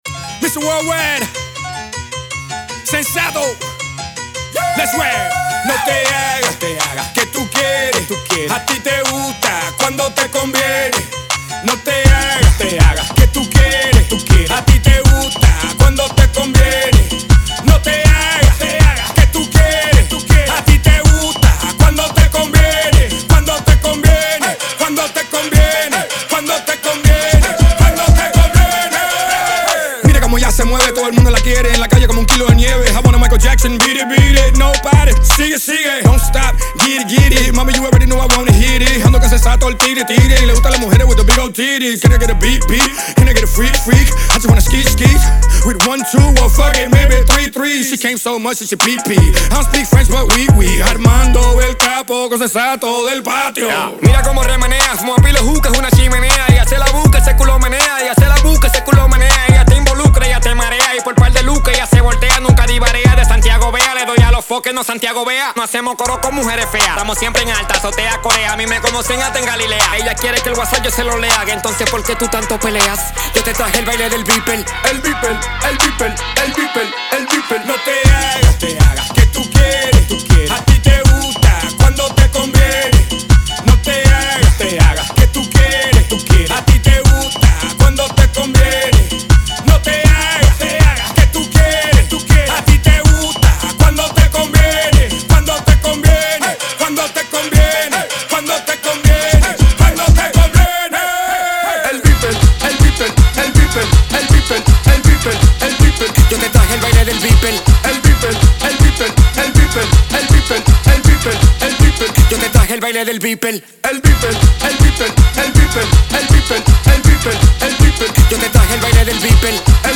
• Жанр: Pop, Hip-Hop